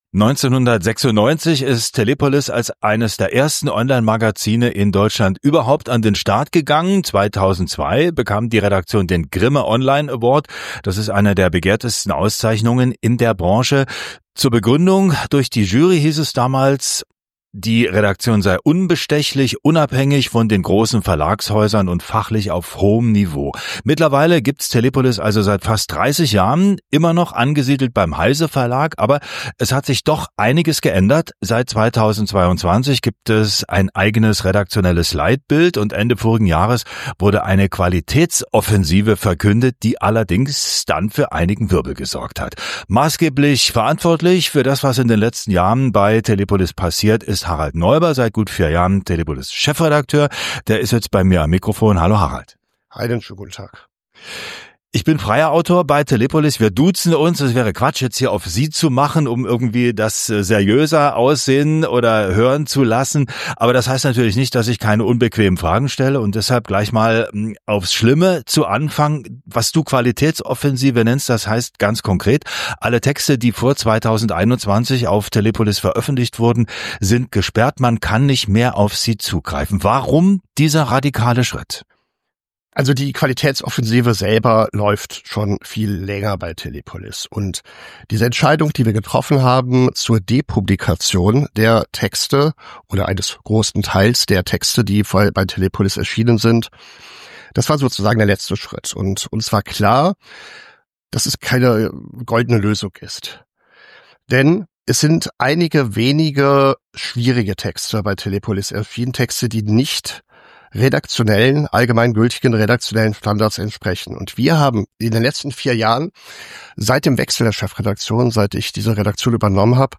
Das Interview gibt spannende Einblicke in den Wandel und die Herausforderungen eines Online-Magazins, das seit fast 30 Jahren existiert.